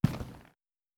Plastic footsteps
plastic4.wav